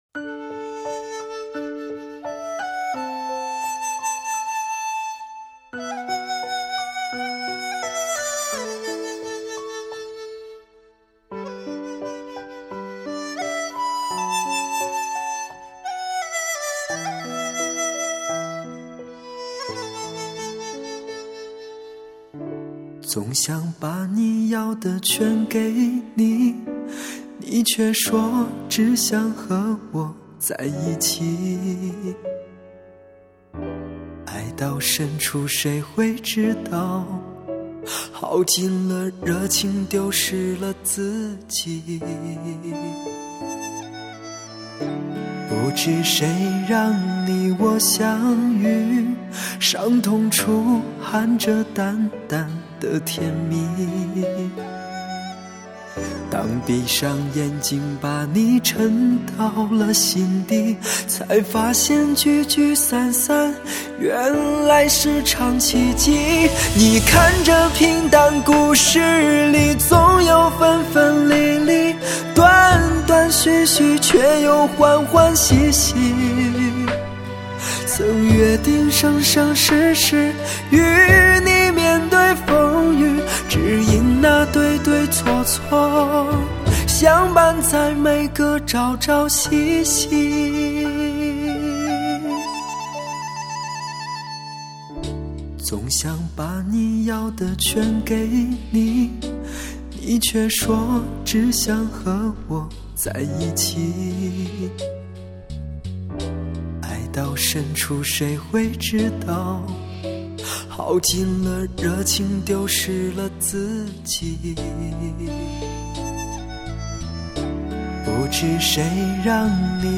抚慰心灵的疗伤音乐
现代发烧深情代表作，首席疗伤音乐男声魅力
风笛、箫、二胡、古筝、琵琶、吉他、弦乐......